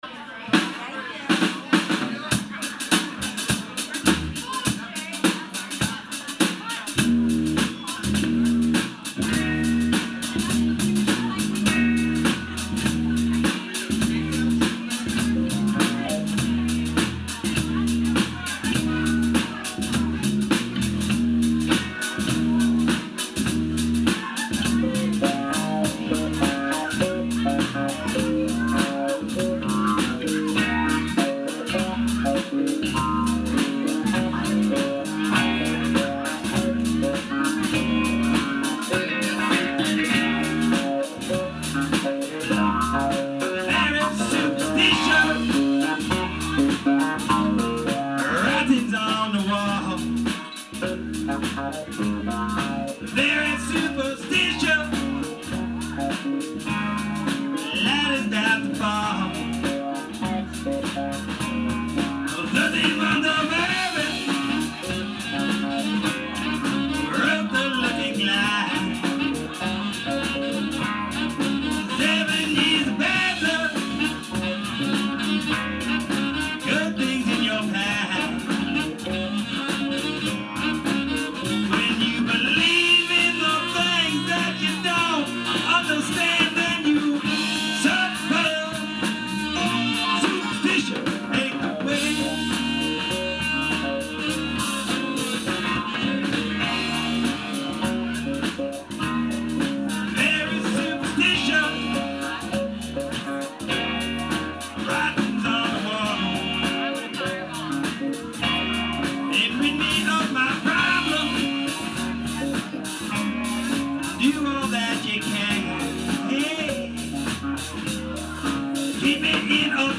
keyboards, backing vocals
sax, flute, tambourine, vocals